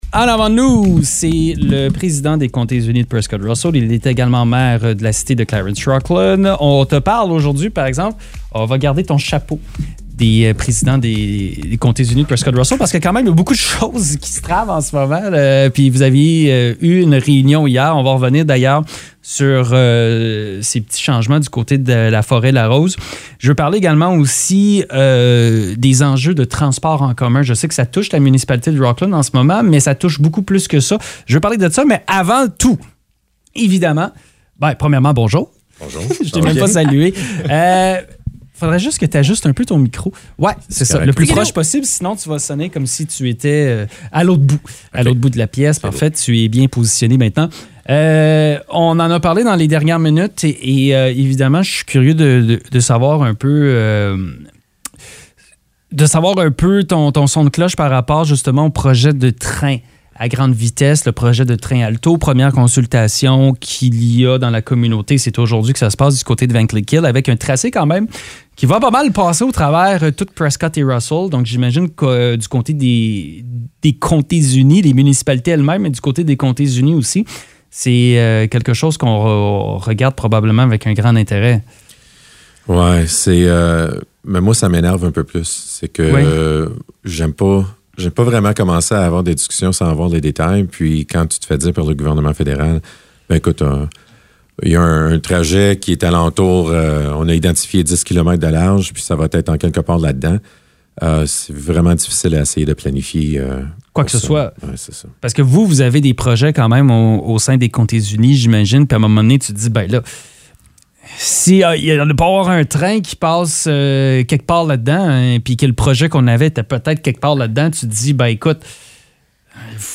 ont reçu en studio le président des Comtés unis de Prescott-Russell et maire de Clarence-Rockland, Mario Zanth.